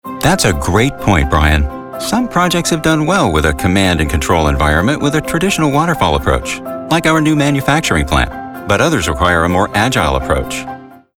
Mature Adult, Adult
Has Own Studio
standard us
04_Agile_Projects_spot.mp3